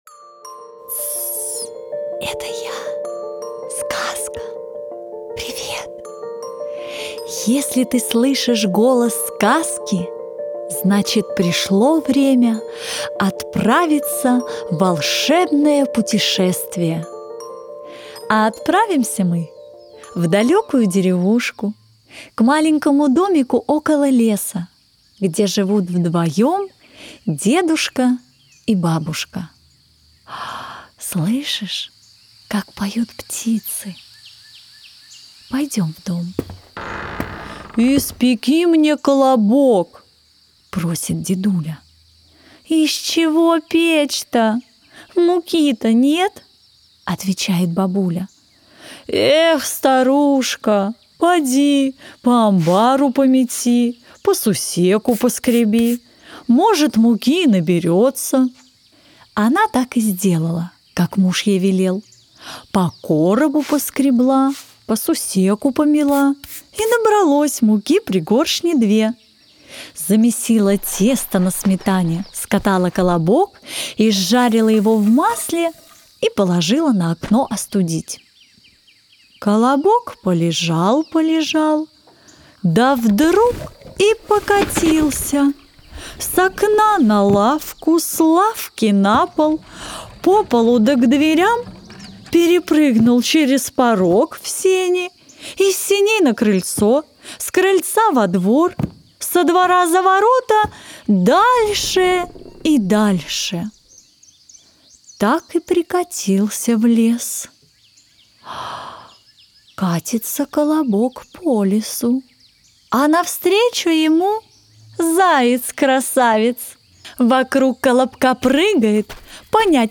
Красивая музыка, звуки природы и немного авторского сюжета в любимую сказку создают атмосферу волшебного путешествия!